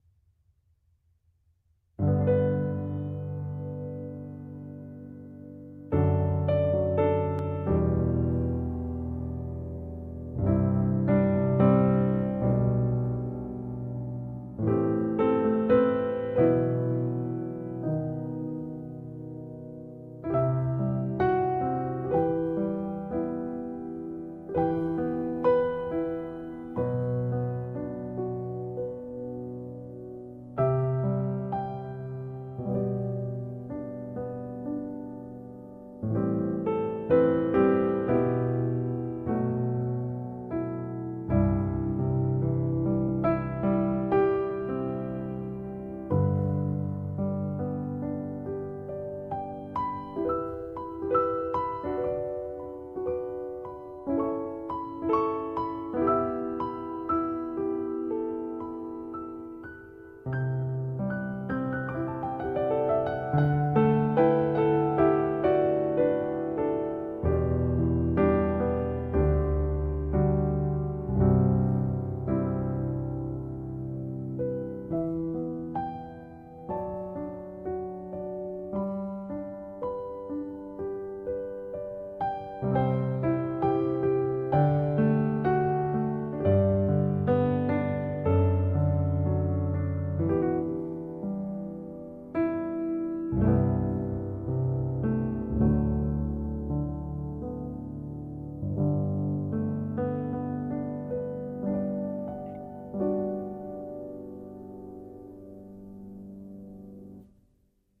Musicalmente, la nostalgia funziona quando c’è una mescolanza dolce e amaro.
La nostalgia ha bisogno di un profilo più neutro, più raccolto, di un’alternanza di chiari e scuri, di una dinamica non troppo alta, di un registro non eccessivamente acuto.